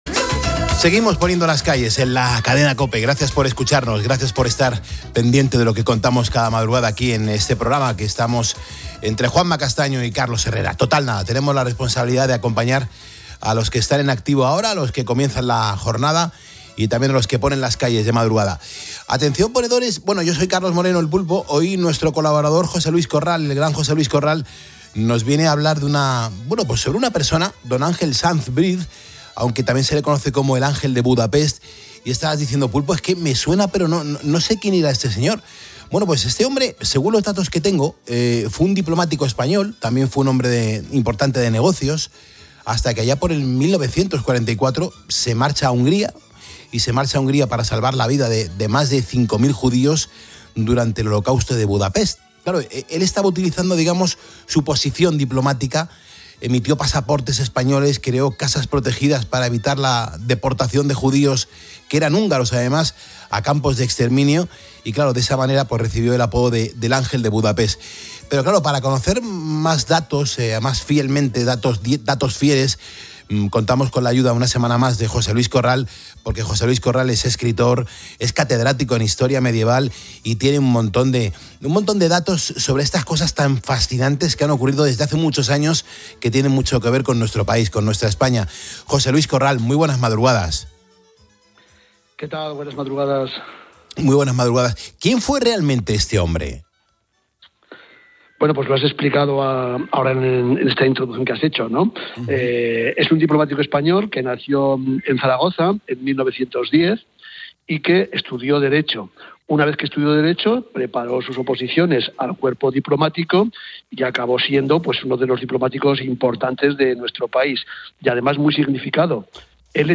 en el programa ' Poniendo las Calles ' de la Cadena COPE